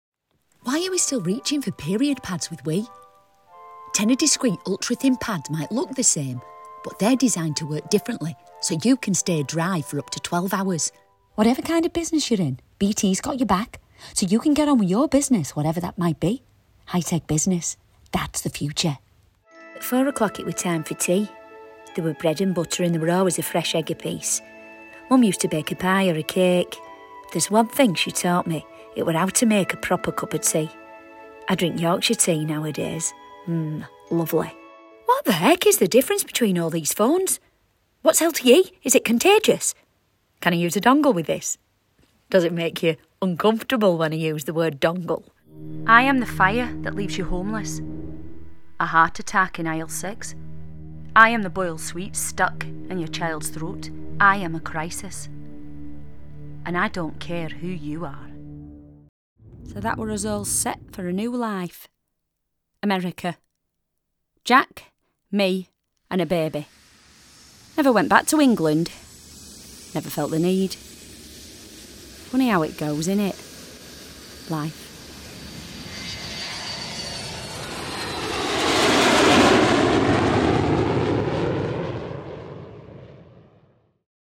Showreel
Female / 30s, 40s / English / Northern Showreel https